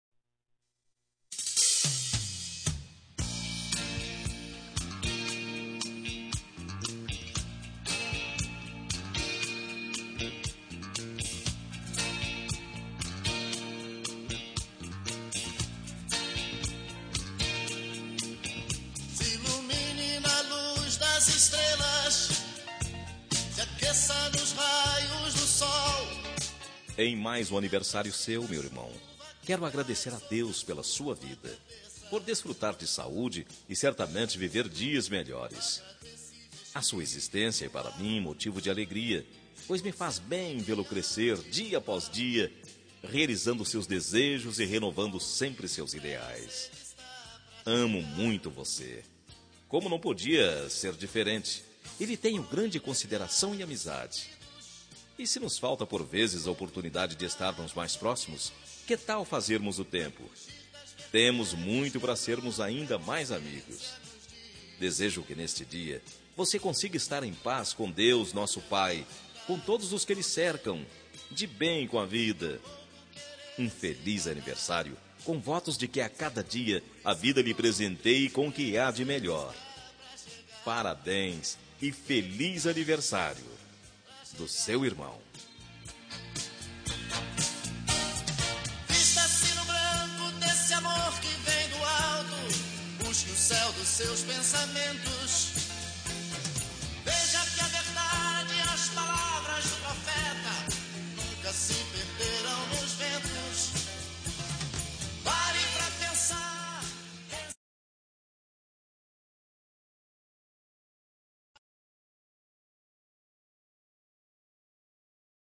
Telemensagem de Aniversário de Irmão – Voz Masculina – Cód: 1743 – Religiosa